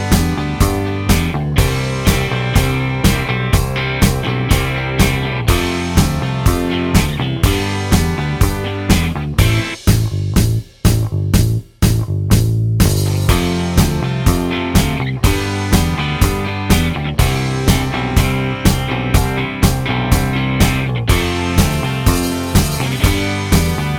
Pop (1980s)